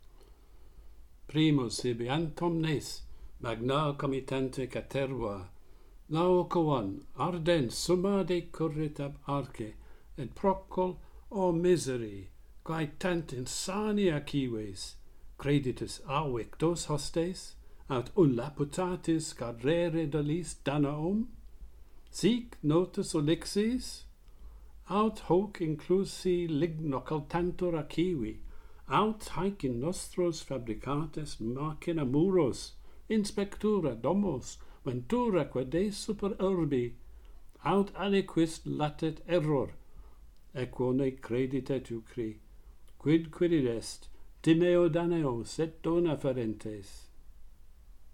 Laocoon warns against the Trojan horse - Pantheon Poets | Latin Poetry Recited and Translated